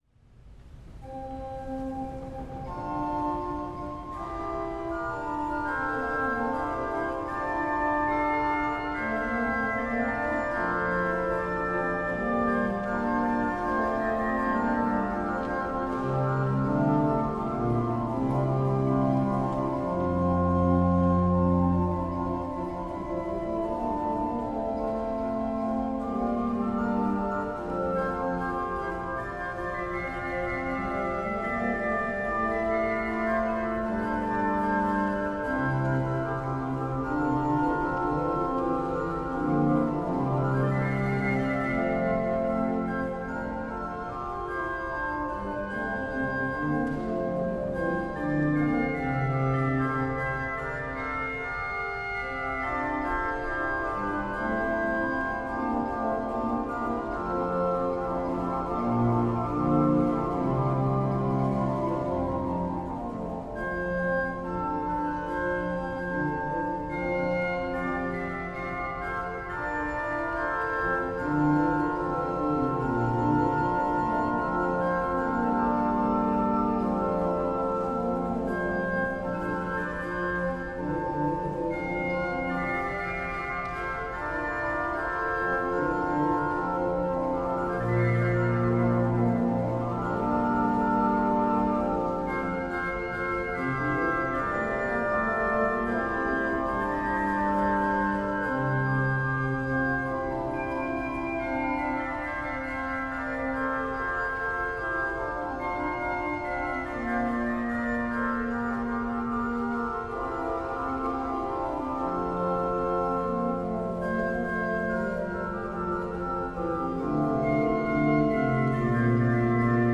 Una prima esecuzione moderna dopo 400 anni.
con brani per organo in alternanza alle parti monodiche e polifoniche